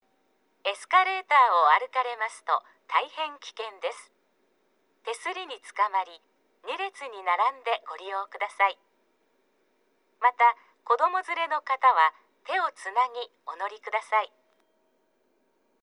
啓発放送（エスカレーター）